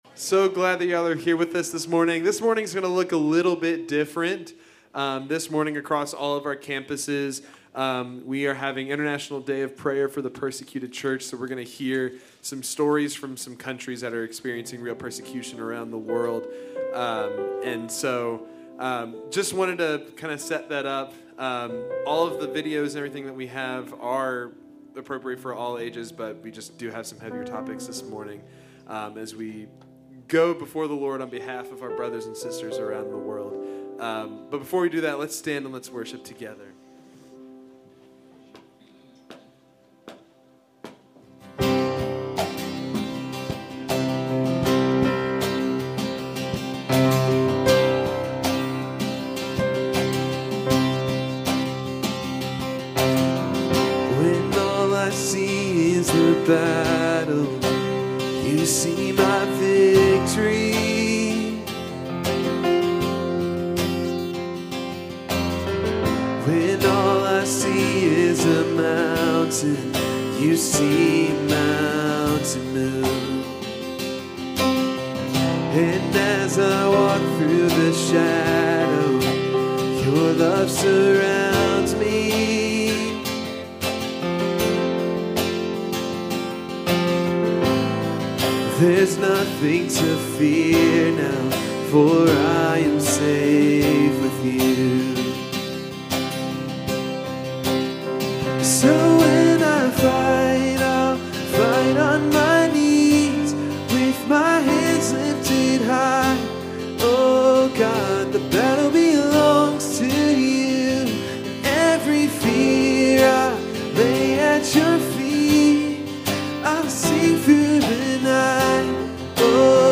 Grace Community Church Dover Campus Sermons 11_9 Dover Campus Nov 10 2025 | 01:00:34 1x 00:00 / 01:00:34 Subscribe Share RSS Feed Share Link Embed